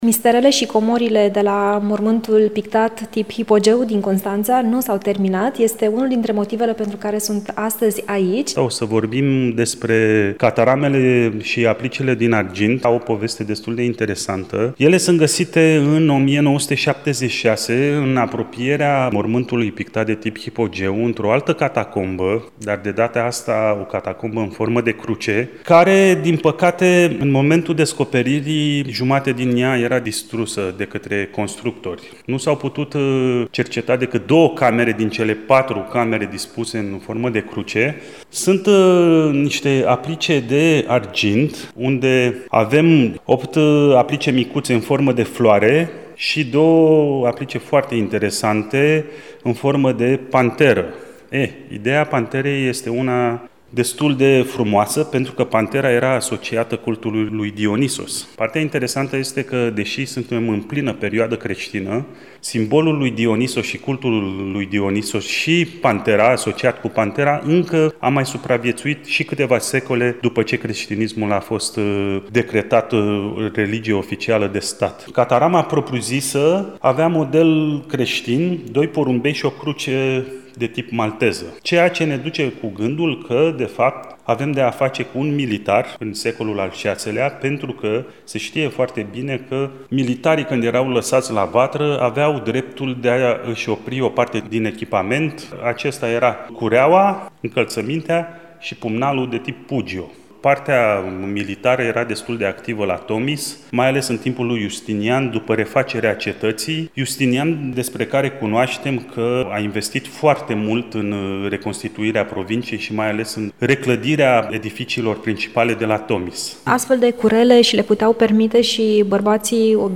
a mers la Mormântul Pictat de tip Hypogeu, unde sunt expuse aceste mini bijuterii arheologice